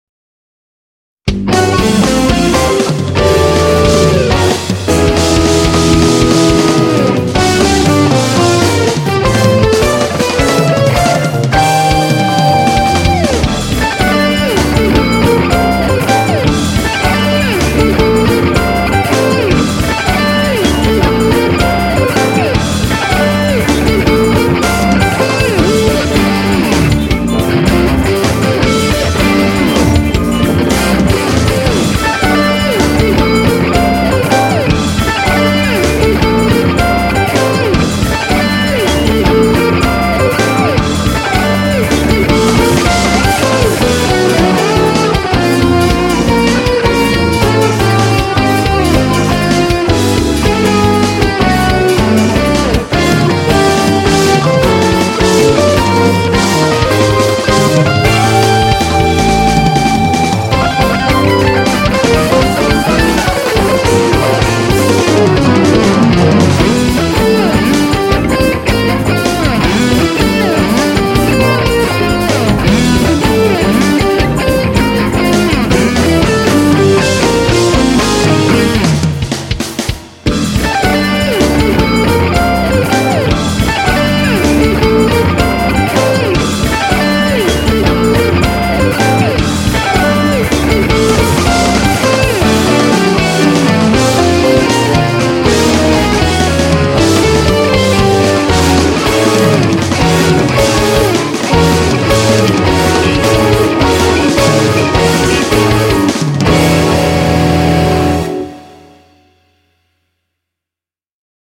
퓨젼곡입니다